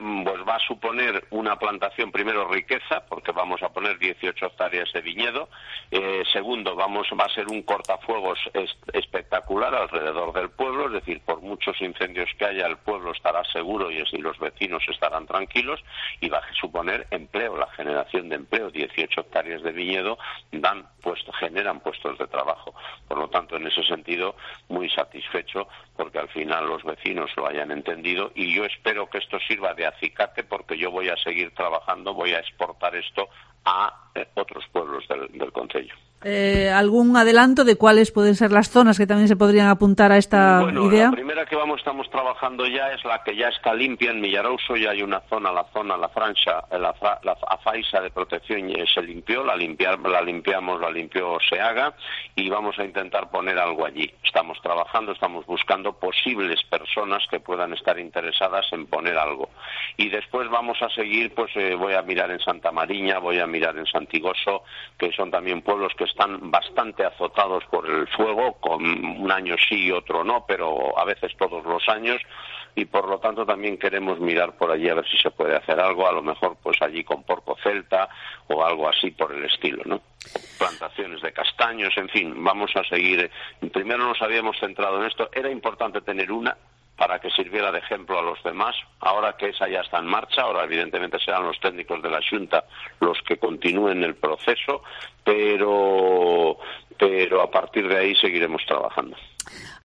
El alcalde de O Barco, Alfredo García, explica lo que supone la aldea modelo